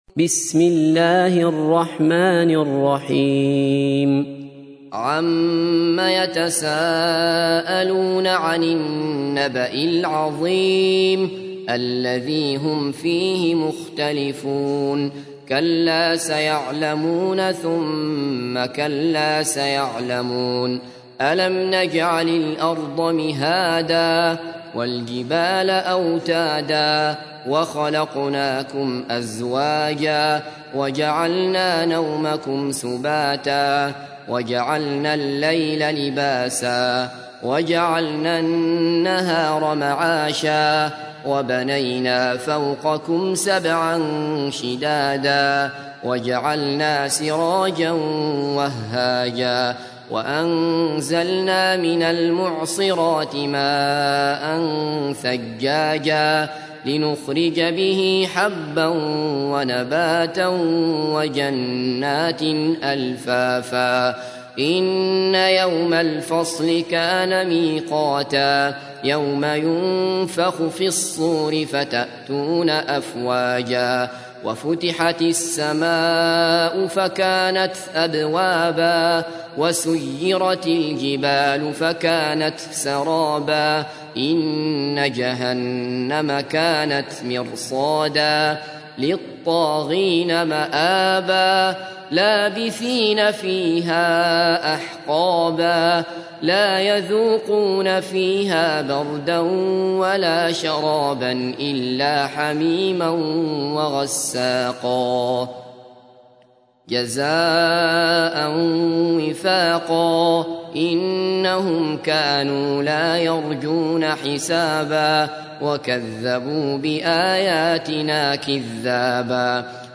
تحميل : 78. سورة النبأ / القارئ عبد الله بصفر / القرآن الكريم / موقع يا حسين